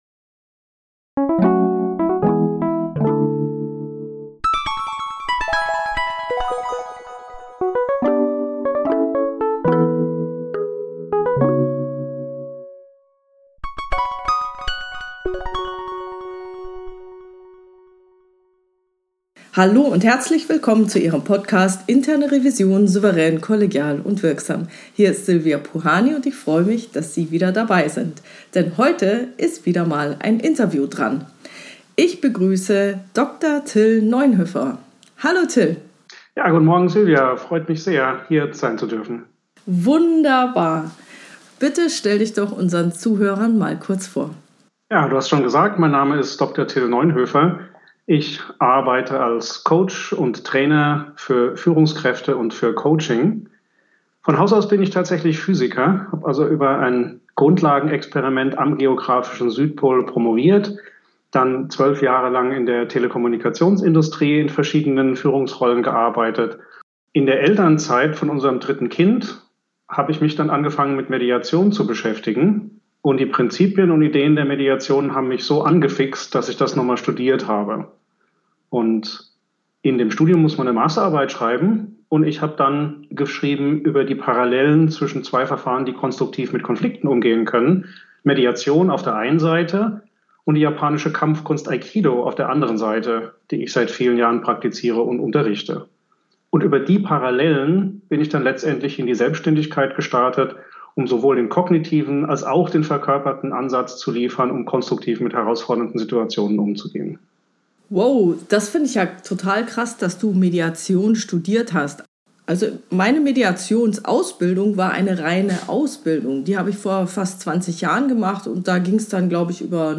Hier ein wunderbares Interview über Konfliktlösung als Weg zur Harmonie mit Konzepten aus dem Aikido, der Mediation und dem hypnosystemischen Coaching.